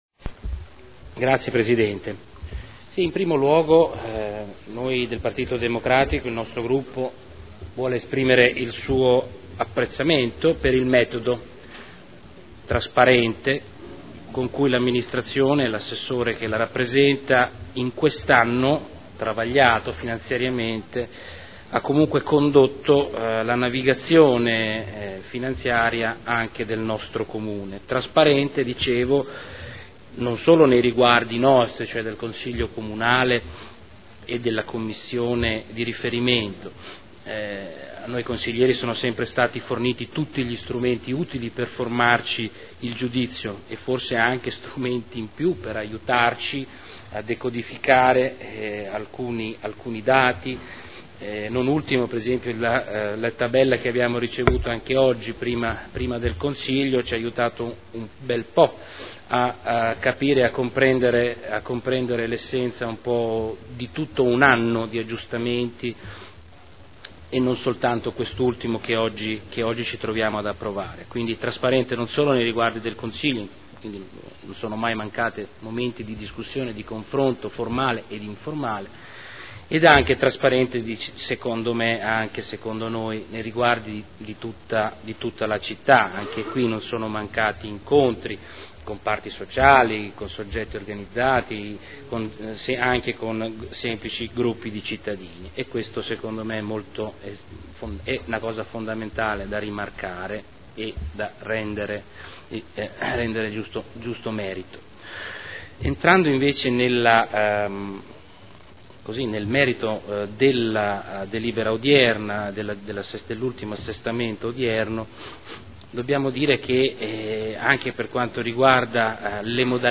Salvatore Cotrino — Sito Audio Consiglio Comunale
Seduta del 28 novembre Proposta di deliberazione Bilancio di previsione 2011 - Bilancio pluriennale 2011-2013 - Programma triennale dei lavori pubblici 2011-2013 - Assestamento - Variazione di bilancio n. 3 Dichiarazioni di voto